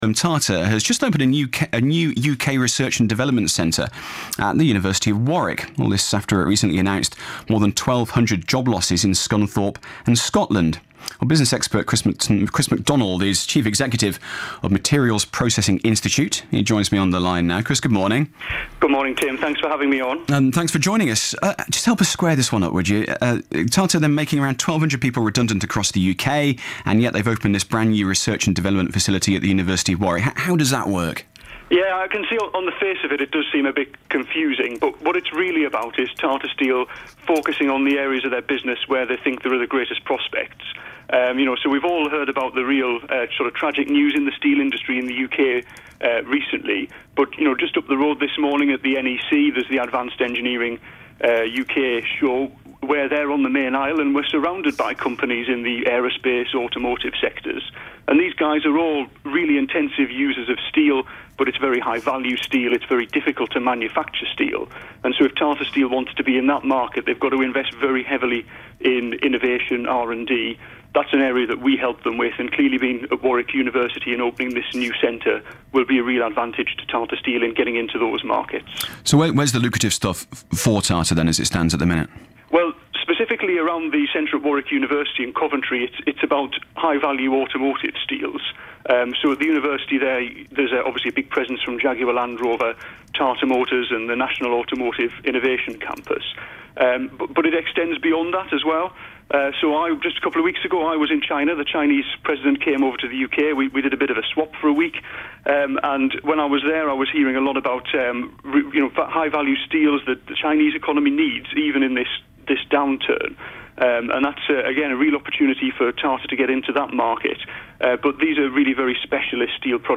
BBC Radio Interview - The Importance of Research in the Steel Industry
BBC-Coventry-Warwickshire-Radio-Interview-The-Importance-of-Research-in-the-Steel-Industry.mp3